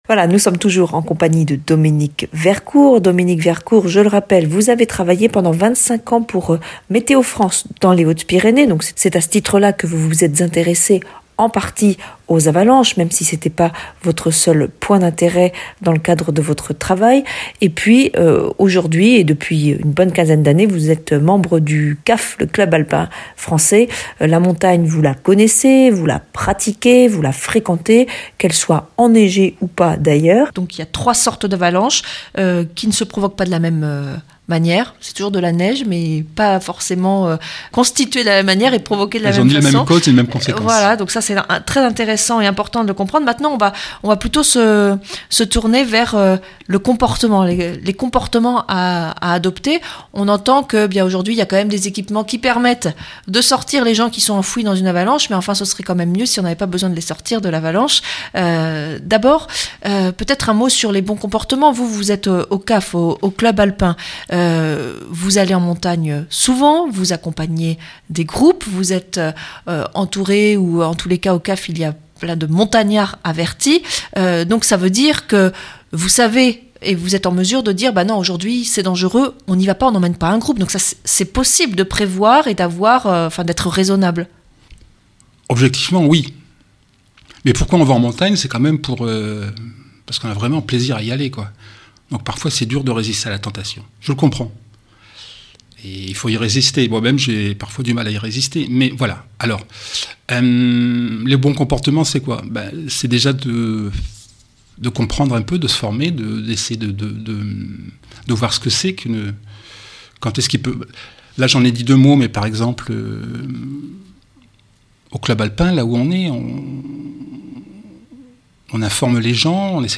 Interview et reportage du 22 janv.